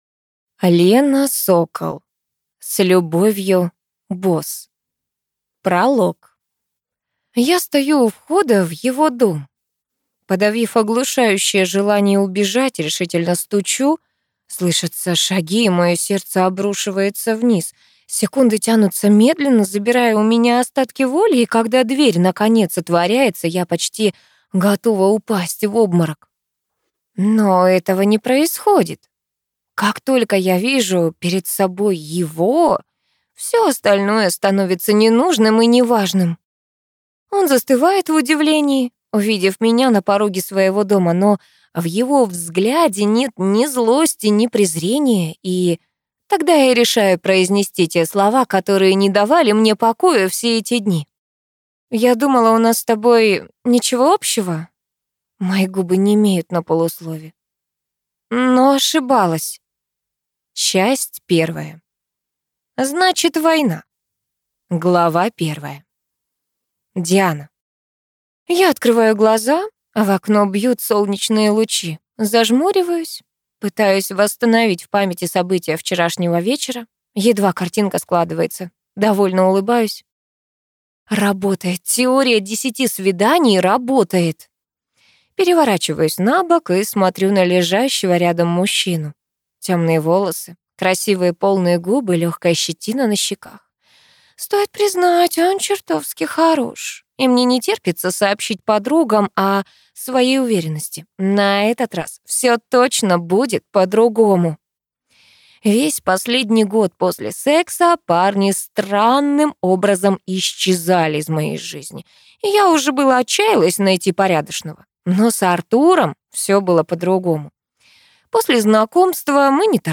Аудиокнига С любовью, Босс | Библиотека аудиокниг